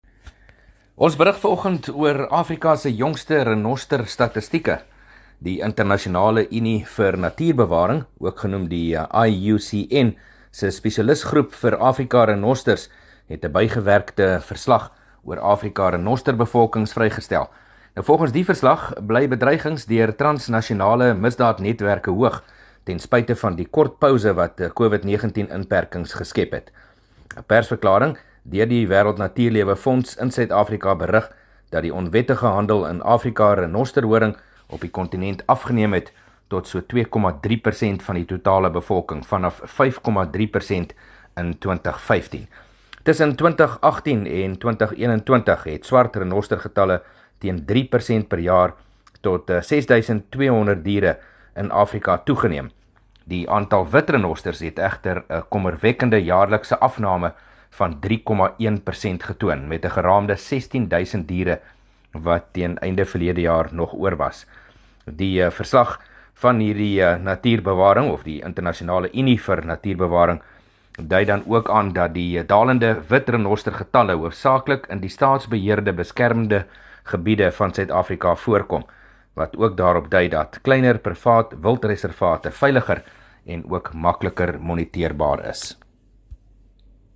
27 Oct PM berig oor die jongste renostergetalle wat onlangs bekend gemaak is